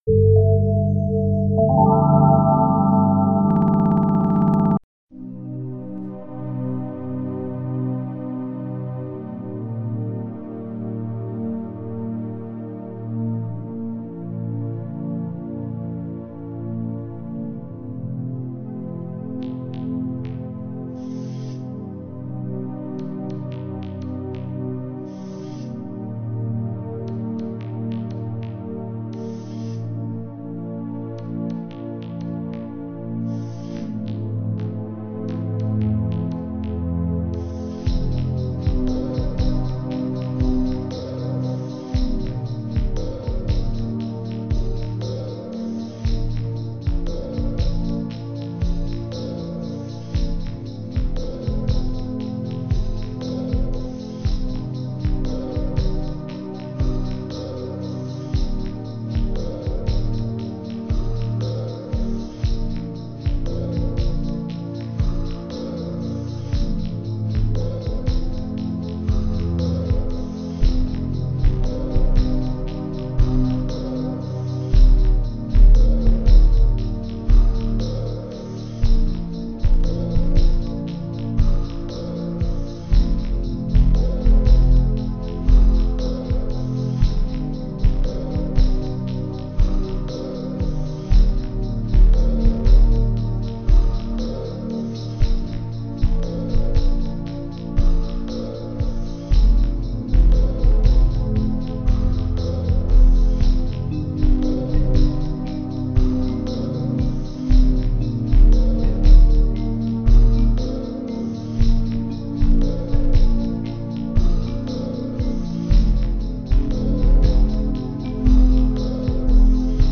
Index of /Music/recovered/vaporwave/